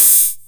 KR55_OH_02.wav